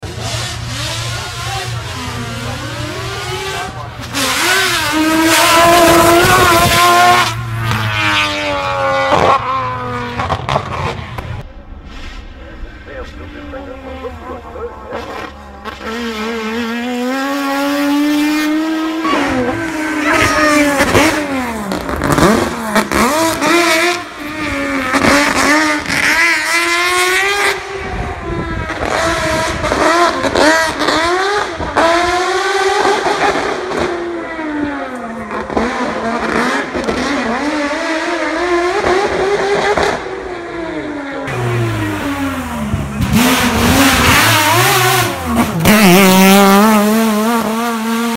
Today’s soothing sounds for car sound effects free download
I will always love all rotary sounds and this car with its racing pedigree is no exception. I’m glad someone saved this car and used it in a hillclimb.